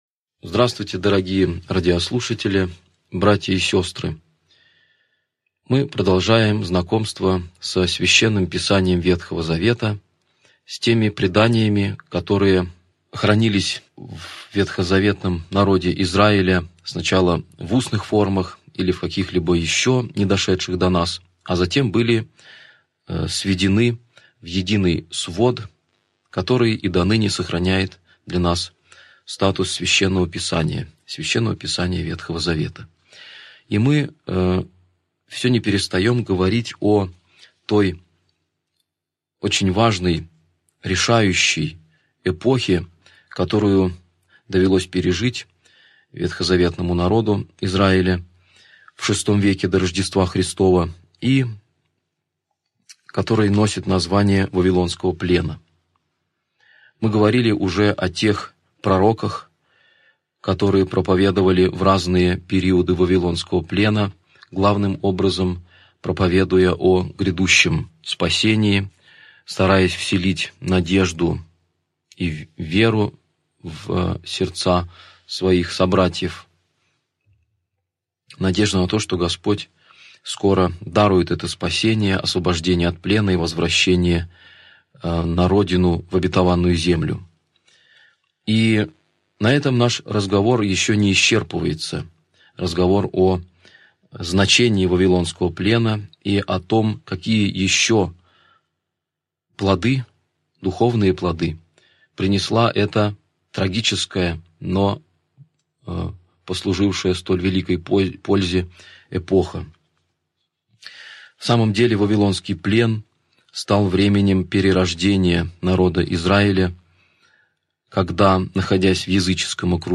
Аудиокнига Лекция 23. Книга Левит | Библиотека аудиокниг